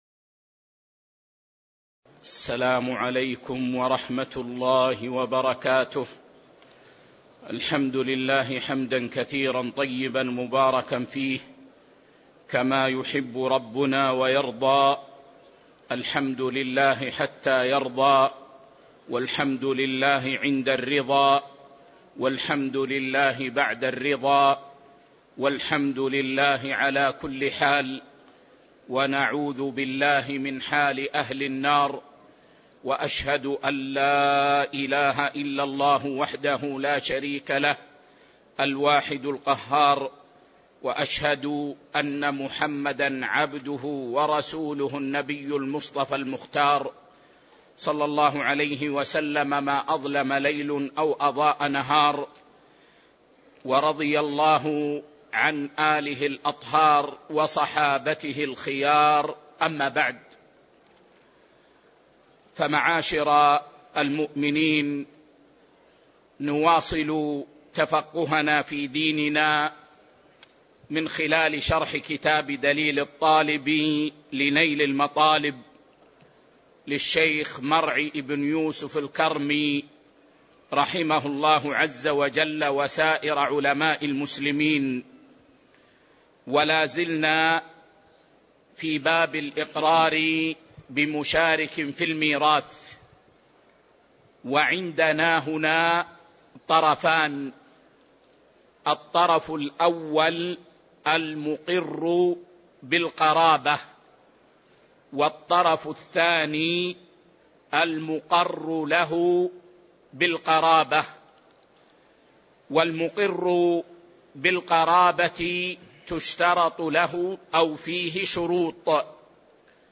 شرح دليل الطالب لنيل المطالب الدرس 328 كتاب الفرائض -[19] باب الإقرار بمشارك في الميراث